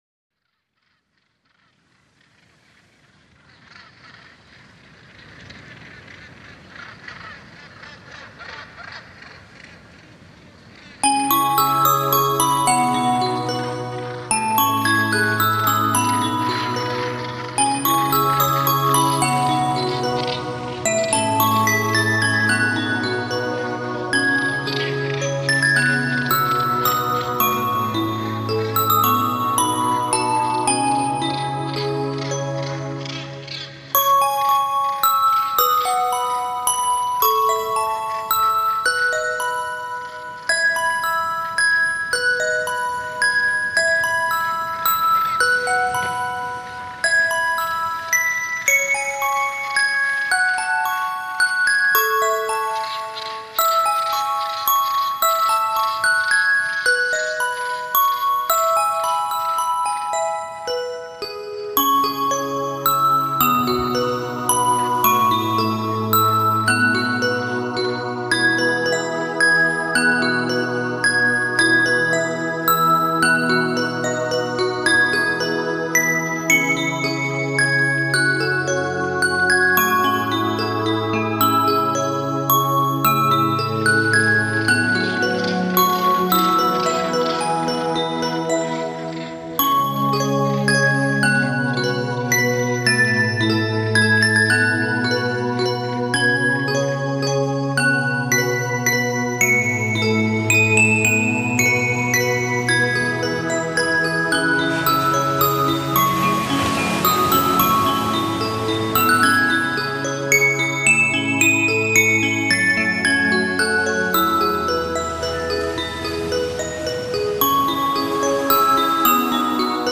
沁凉透澈的琉璃，悠然写意的竖笛，浑然忘我的吉他，拍岸激扬的浪花，叫声不绝的海鸟，共谱一曲曲涤尘忘俗的大自然音乐。